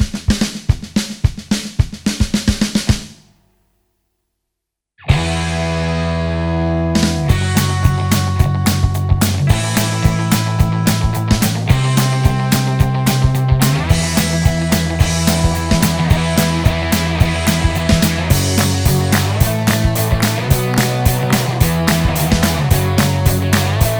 no Backing Vocals Glam Rock 3:53 Buy £1.50